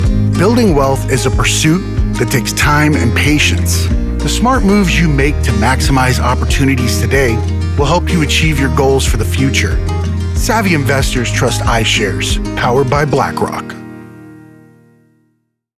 COMMERCIAL
Shares by BlackRock - Trustworthy Financial Narration